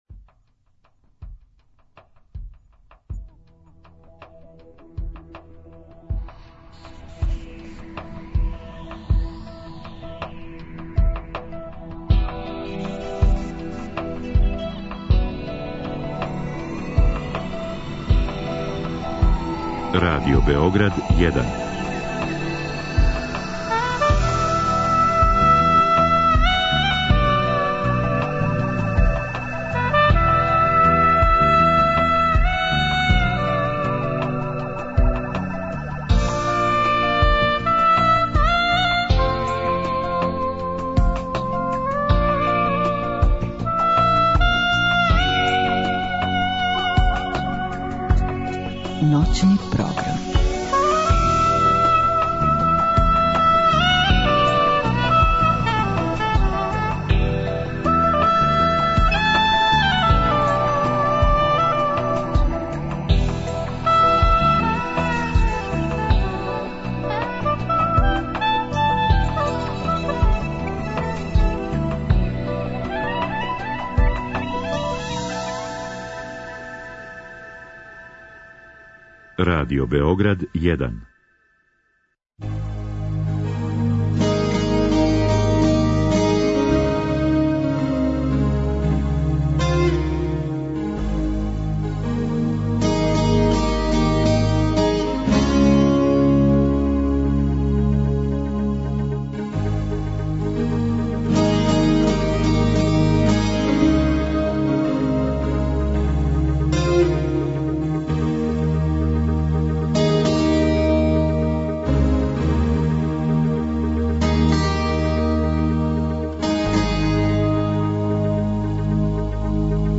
Хип хоп састав Рома Сијам стиже у Сновреме са својом музиком и занимљивим причама: о упознавању, почецима, наступима, турнејама...